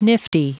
Prononciation du mot nifty en anglais (fichier audio)